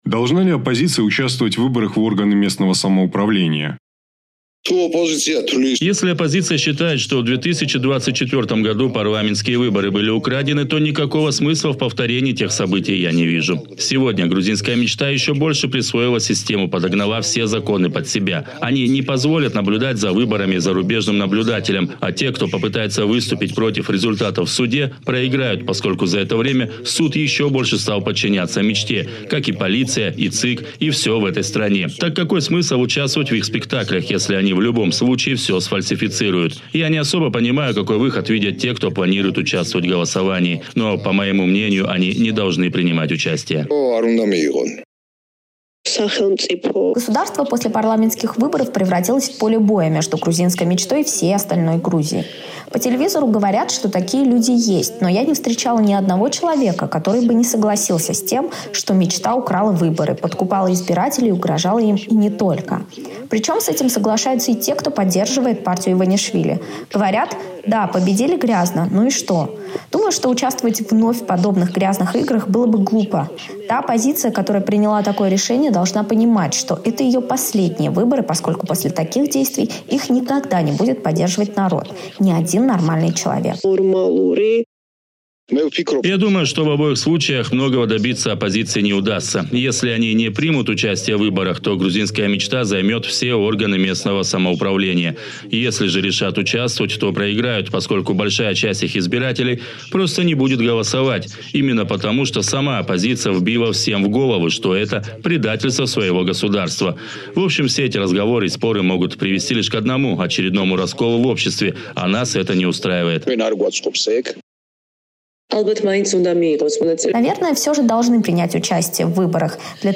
Какое решение должна принять оппозиция в вопросе участия в выборах в органы местного самоуправления? На вопрос «Эха Кавказа» отвечают пользователи соцсетей